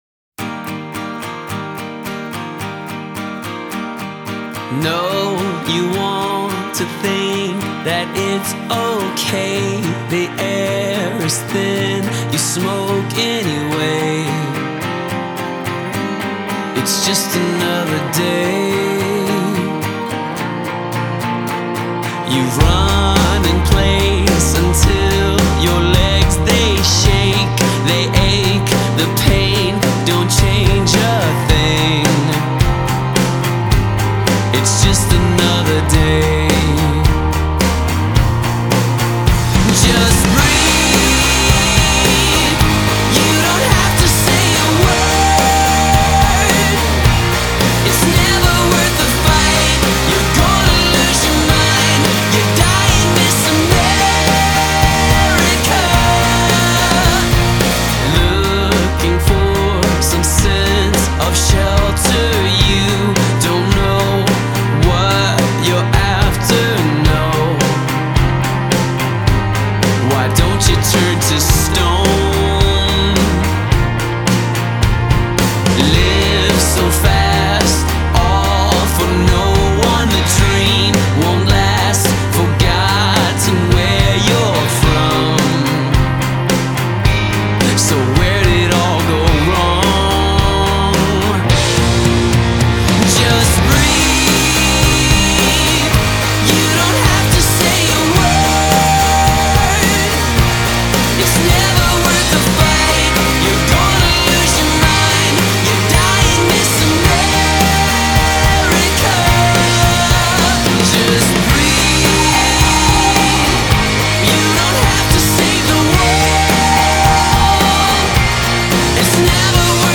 Style: Indie Pop